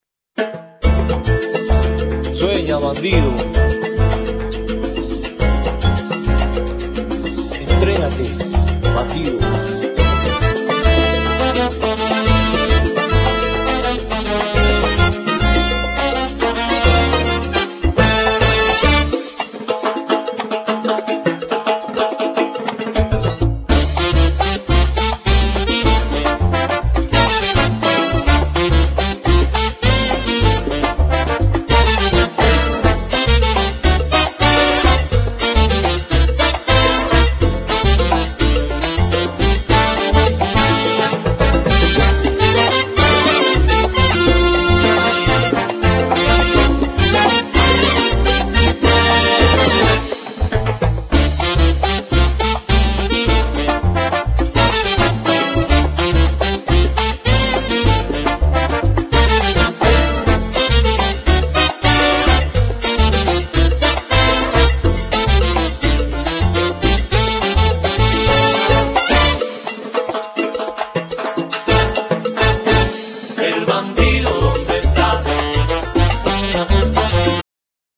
Genere : Pop Latin